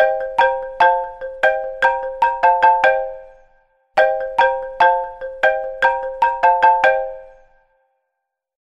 iPhone Ringtones